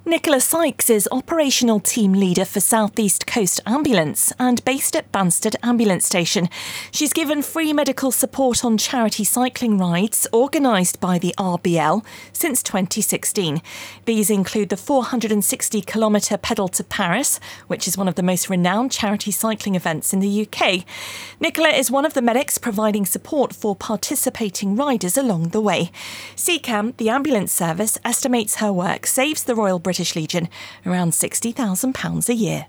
Local News